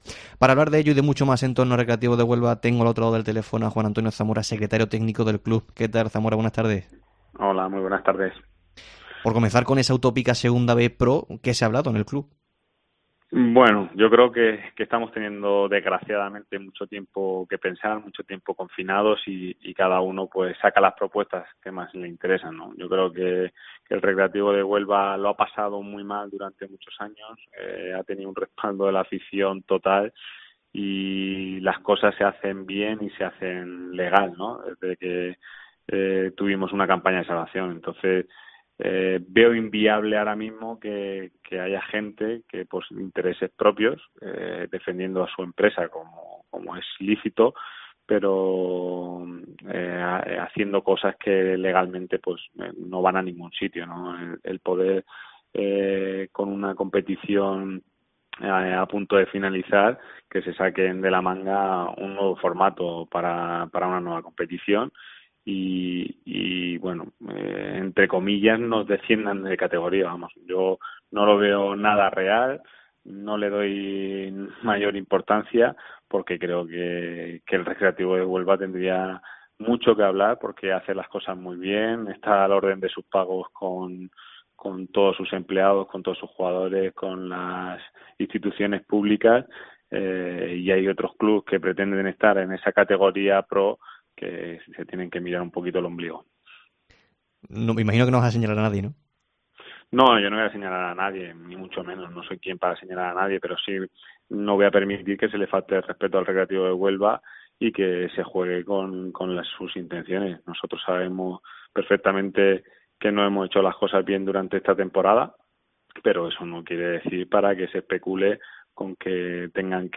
FÚTBOL